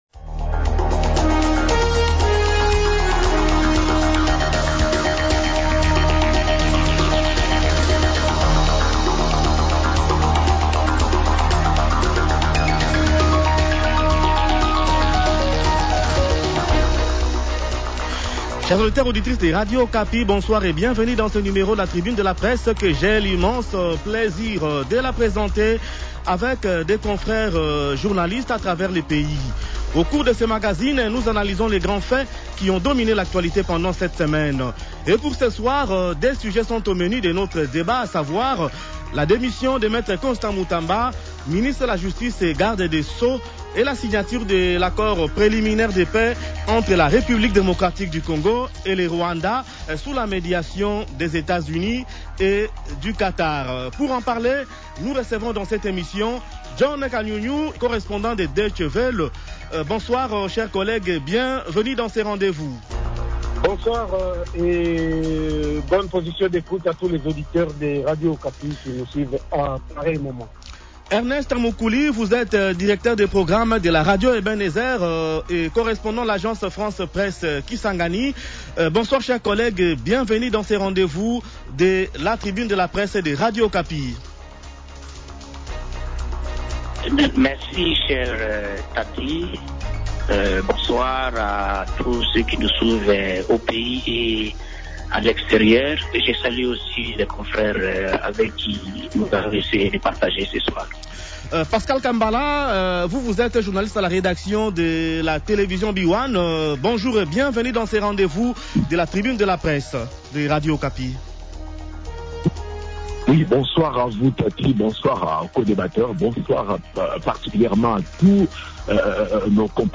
Tribune de presse : démission de Constant Mutamba à la tête du minisère de la Justice | Radio Okapi